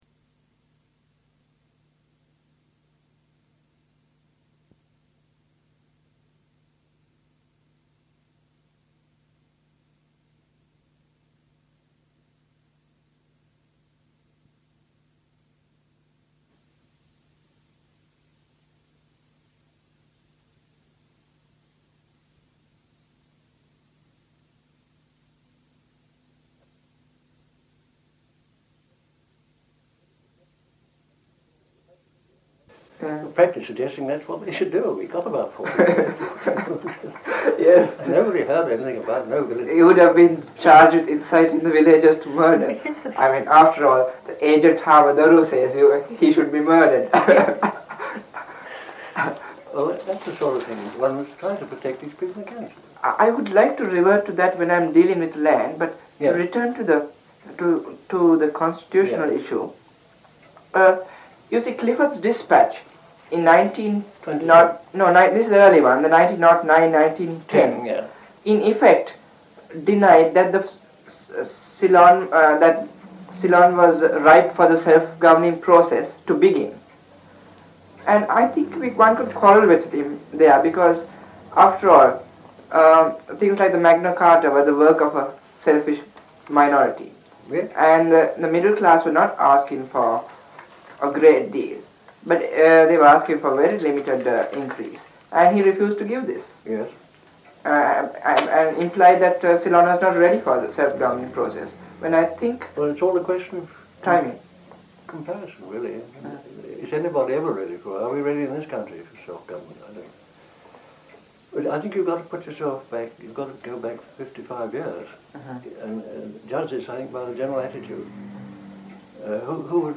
Oral History Project.